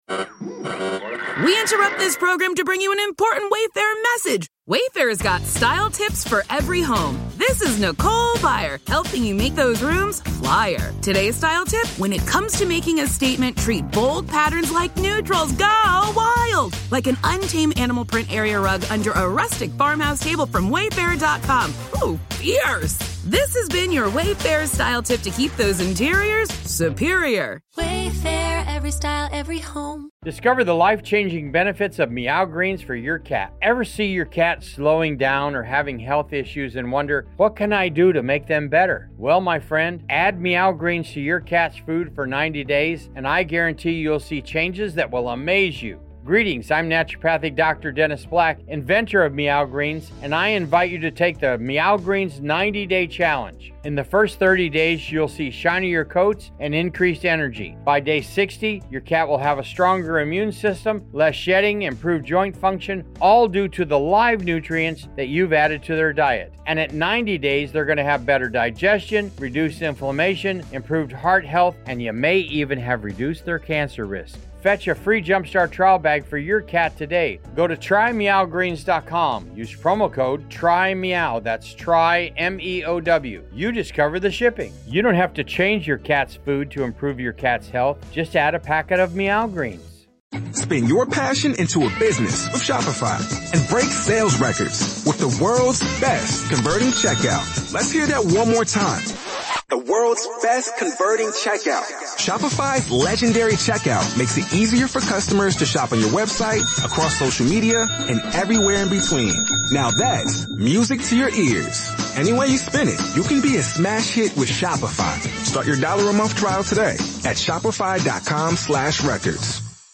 The Air Adventures of Biggles was a popular radio show that ran for almost a decade in Australia, from 1945 to 1954. It was based on the children's adventure novels of the same name by W.E. Johns, which chronicled the exploits of Major James Bigglesworth, a World War I flying ace who continued to have thrilling adventures in the years that followed.